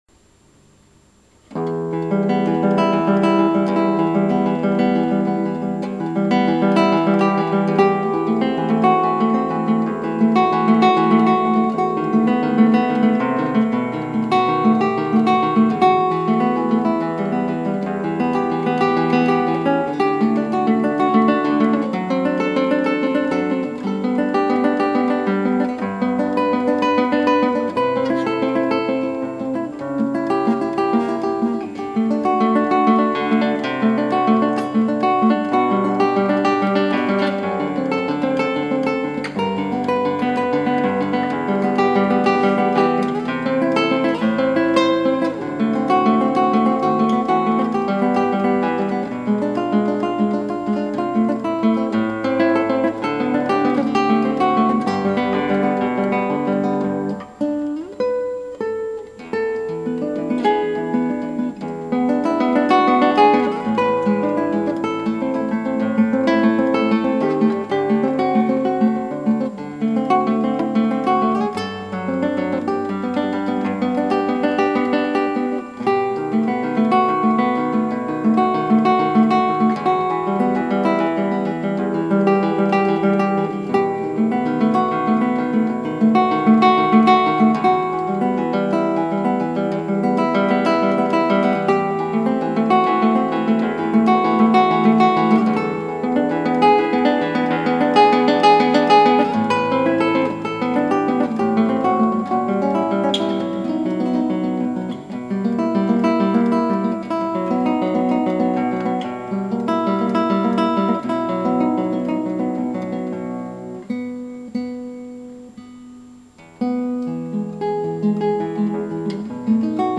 クラシックギター　ストリーミング　コンサート
この曲、メロディーは４分音符と２分音符だけでいってます。そのシンプルなメロディーにアルペジォを散りばめて美曲に仕上げてます。